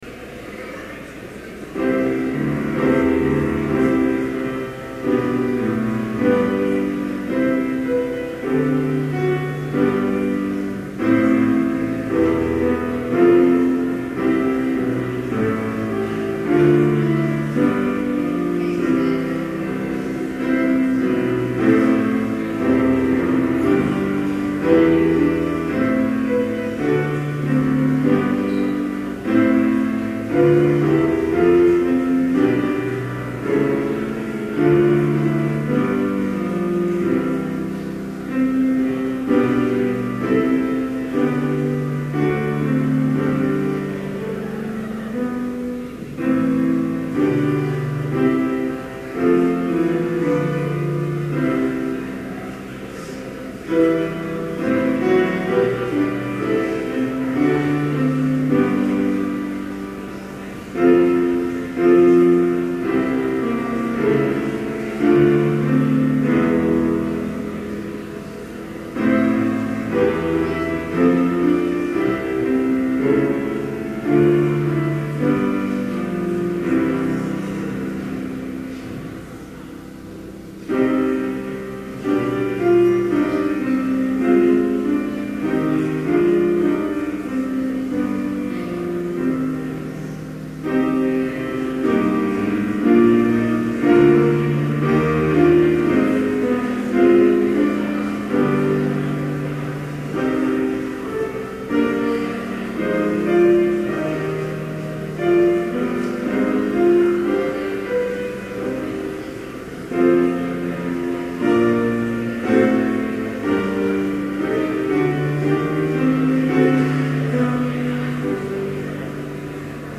Complete service audio for Chapel - October 31, 2011